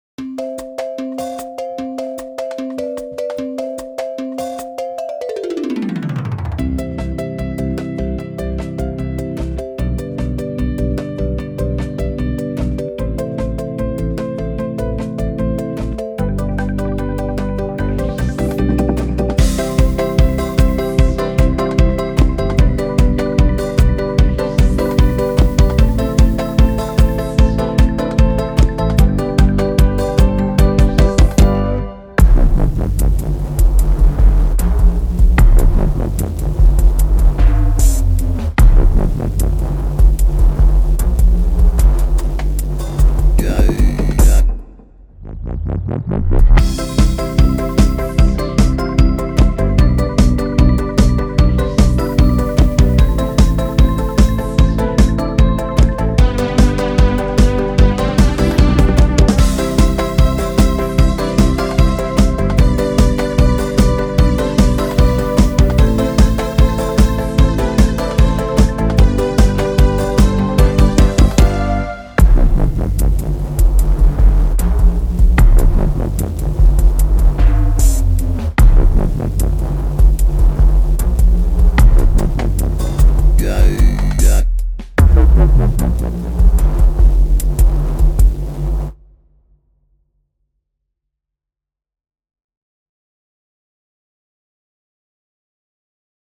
Karaoke versija